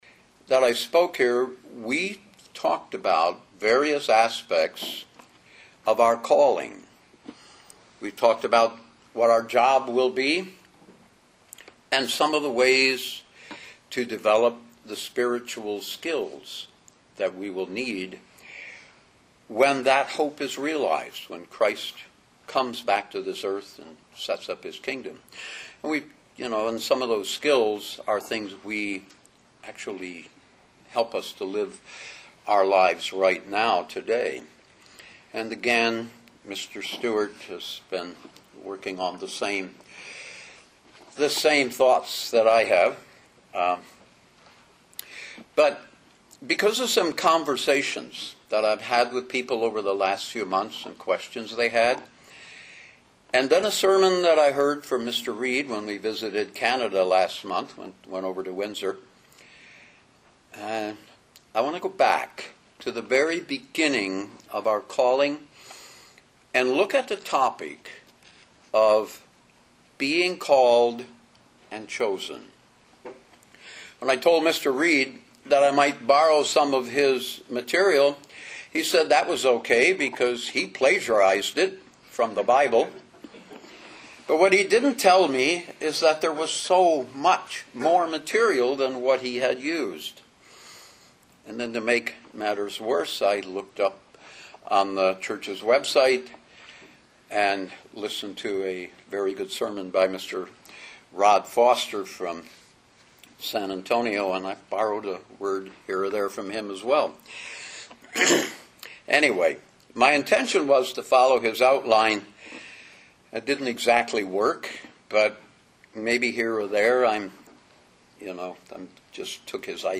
Sermons
Given in Detroit, MI